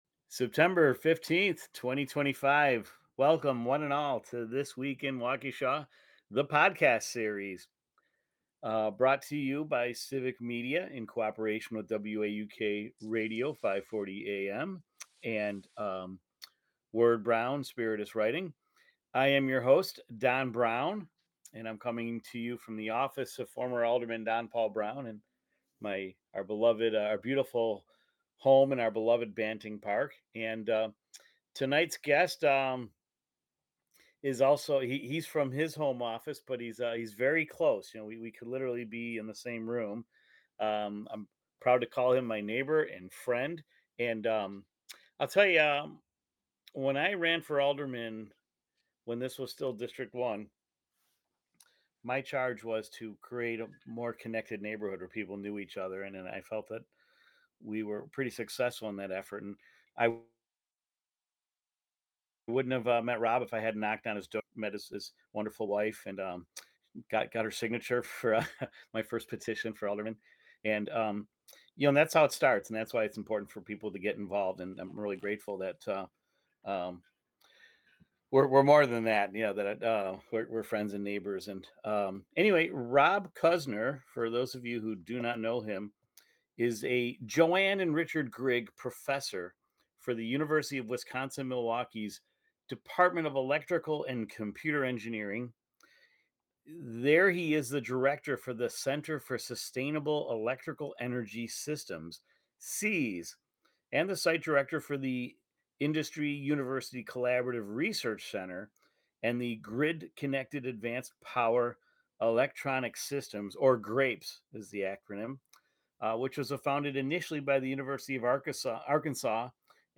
He shares his journey from engineering in Wisconsin’s manufacturing hubs to earning his PhD later in life, and how his family, career, and community have shaped his path. Tune in for a conversation that blends innovation, perseverance, and Waukesha roots.